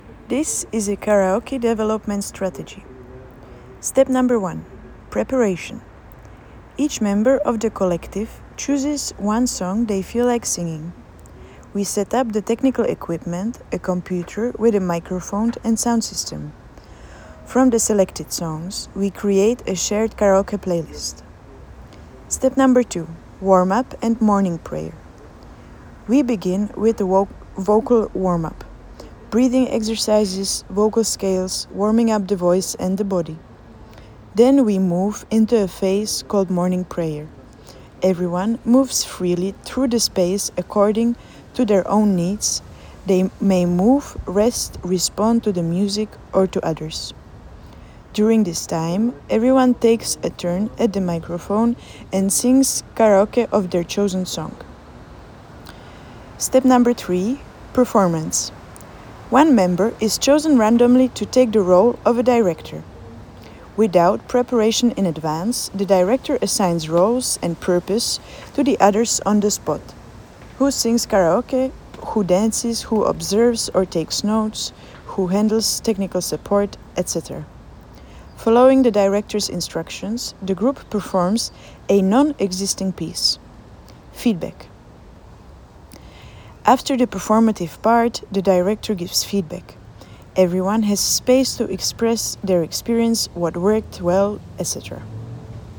en Practicing singing and voice in a performance set-up.
en Singing
en Karaoke